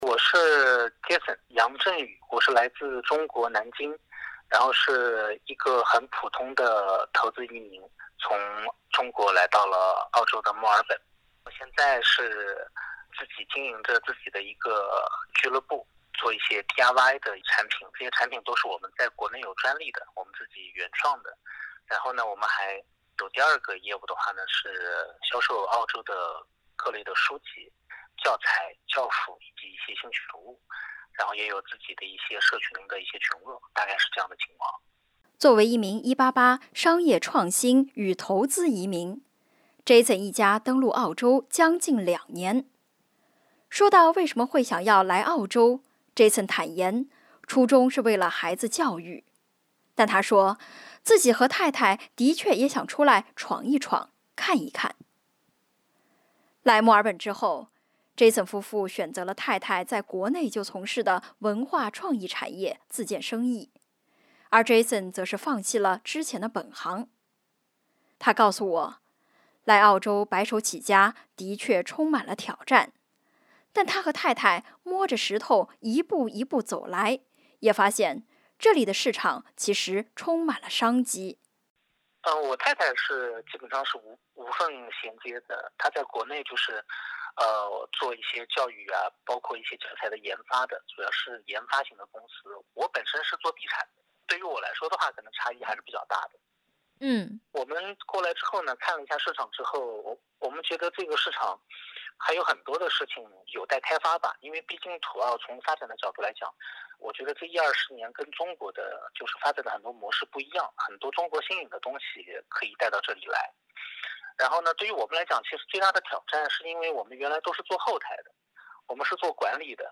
点击图片收听详细报道。